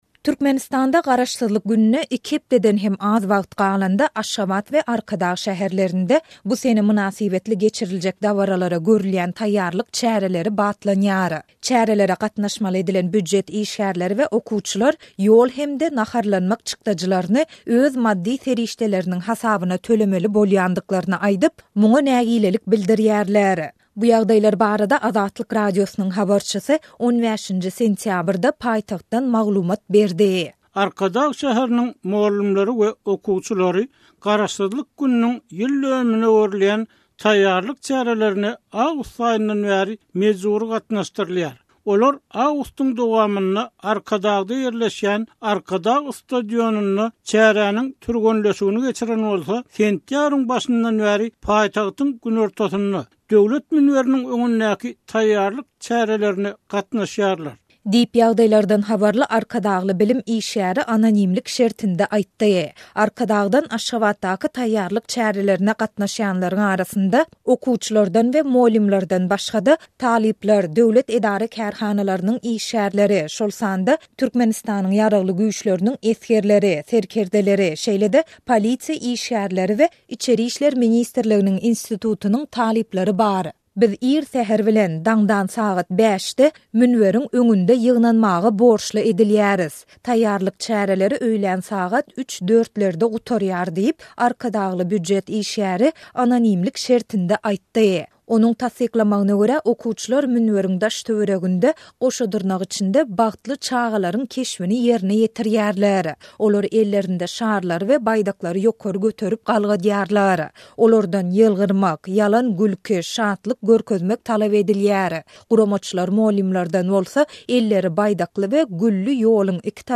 Türkmenistanda Garaşsyzlyk gününe iki hepdeden hem az wagt galanda, Aşgabat we Arkadag şäherlerinde bu sene mynasybetli geçiriljek dabaralara görülýän taýýarlyk çäreleri batlanýar. Bu ýagdaýlar barada Azatlyk Radiosynyň habarçysy 15-nji sentýabrda paýtagtdan maglumat berdi.